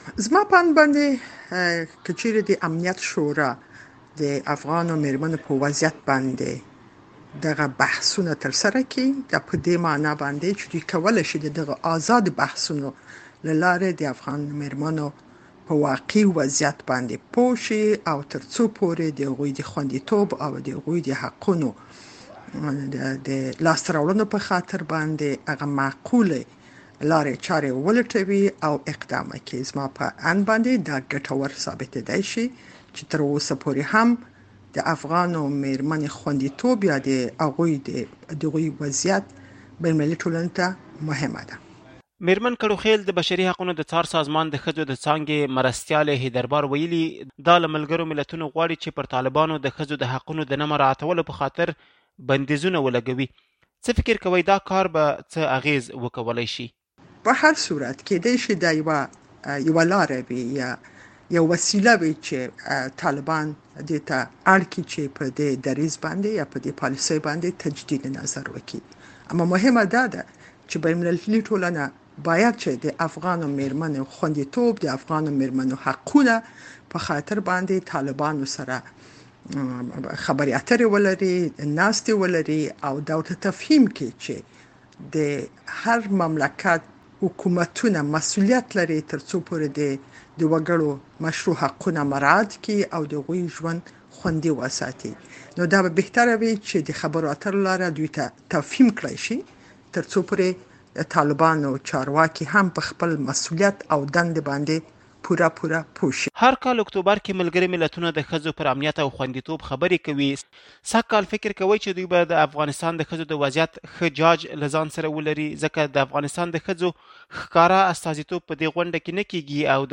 د شينکۍ کړوخېل مرکه